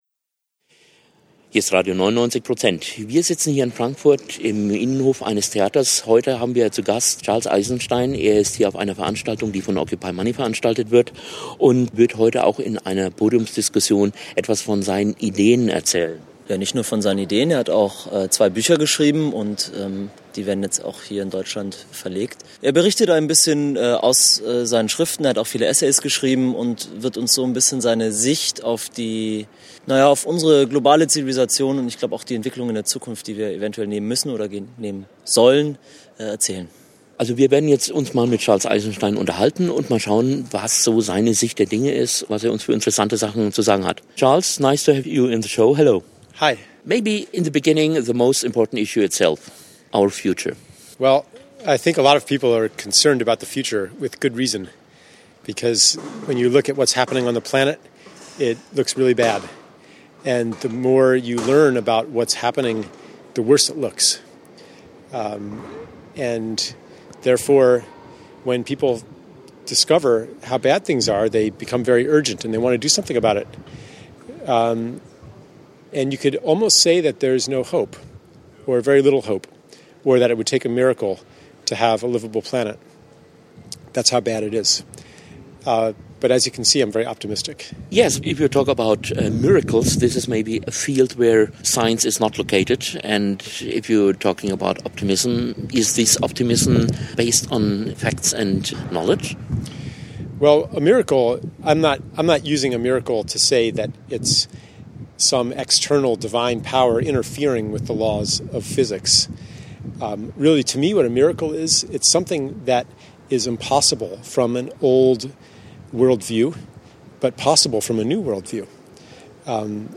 Unterhaltung mit Charles Eisenstein